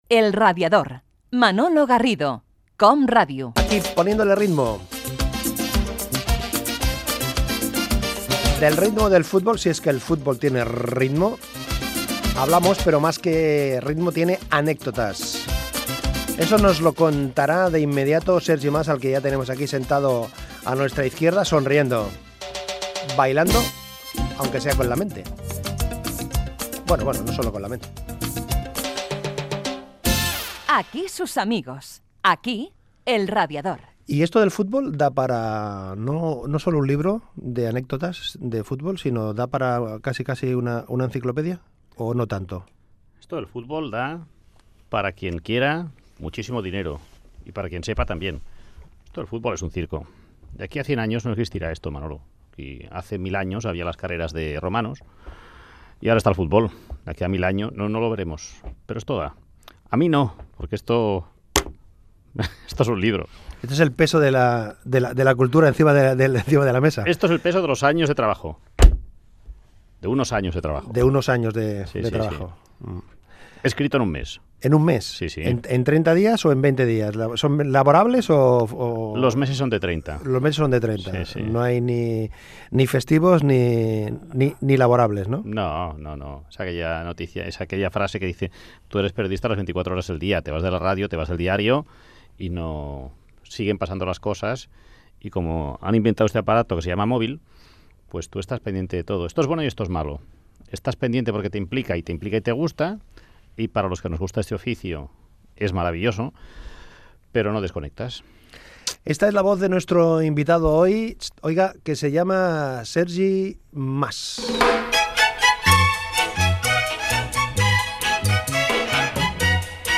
Entreteniment
Fragment extret de l'arxiu sonor de COM Ràdio.